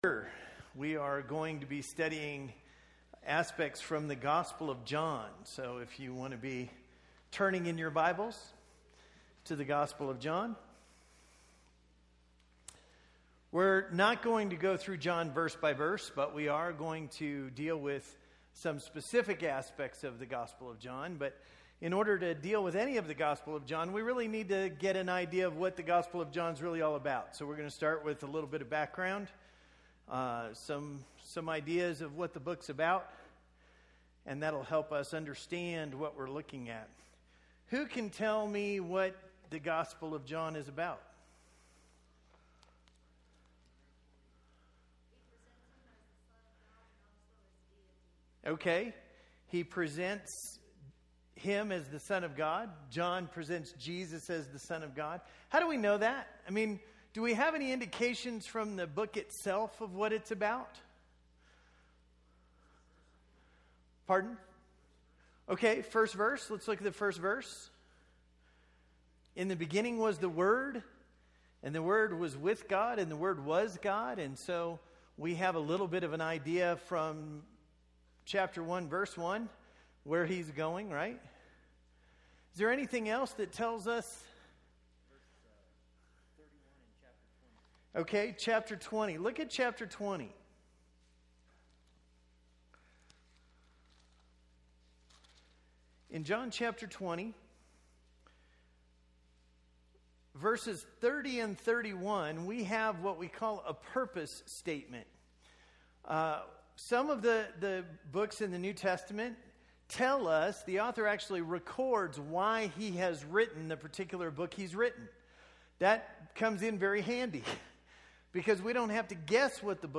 This is a study of the I AM statements of Jesus in the Gospel of John. Tonight's class deals with the background of the I AM statements and some of the general occurrences throughout the Gospel. These presentations are part of the Wednesday night adult Bible classes at the Bear Valley church of Christ.